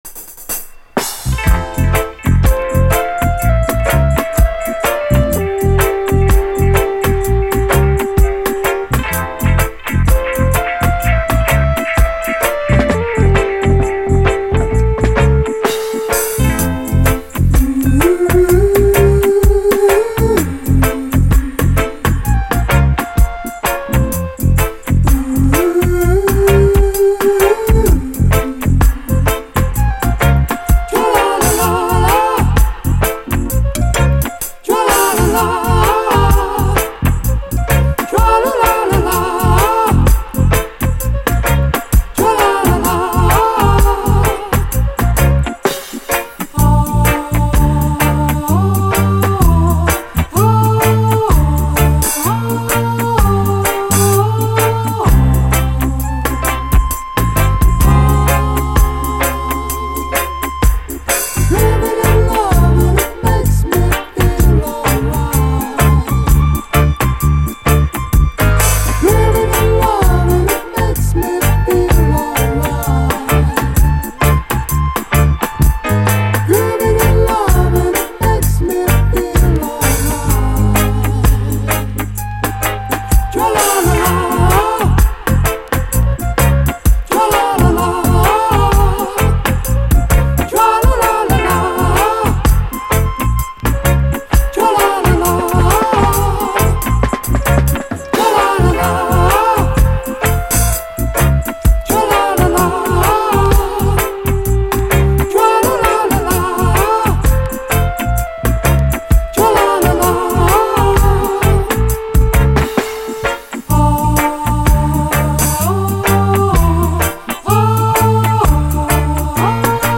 REGGAE
耳に残るコーラス・フレーズ！鬼メロウ。
後半はダブに展開。